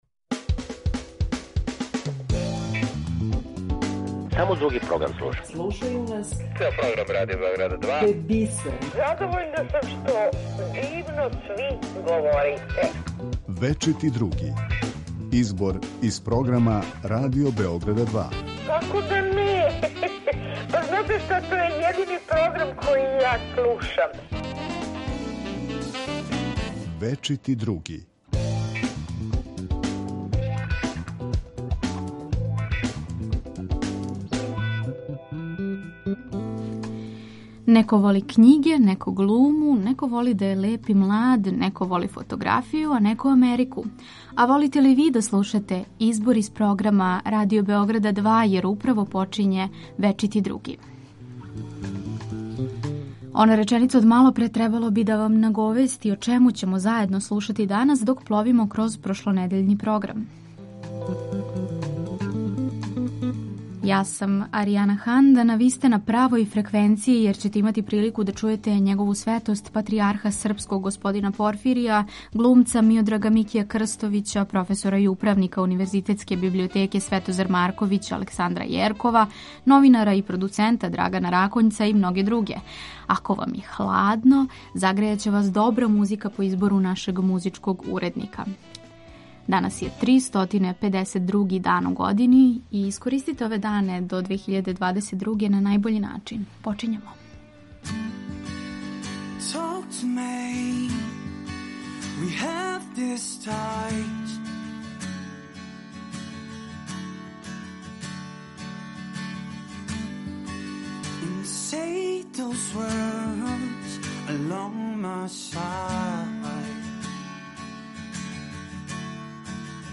У недељном избору из програма Радио Београда 2 за вас издвајамо делове из емисија: Клуб 2, Речено и прећутано, Бескрајни плави круг, Говори да бих те видео, Спорови у култури...
Поред тога што издвајамо делове из прошлонедељног програма, најавићемо и неке од емисија које ћете тек чути на Радио Београду 2.